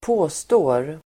Uttal: [²p'å:stå:r]